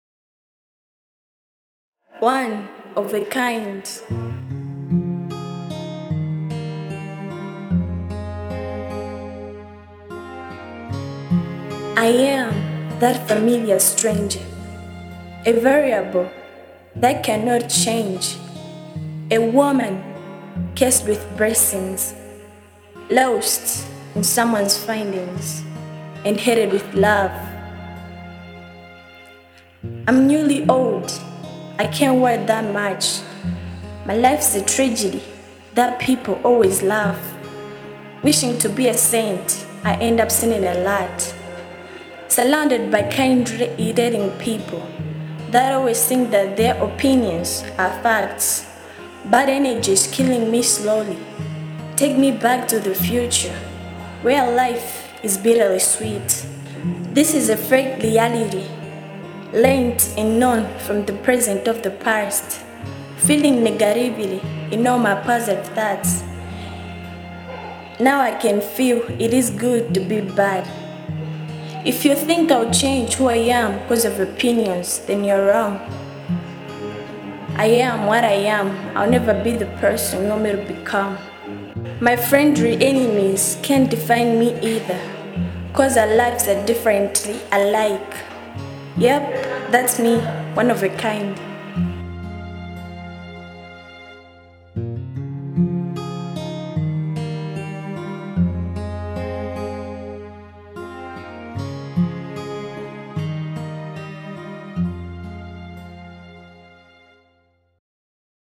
Genre : Spoken Word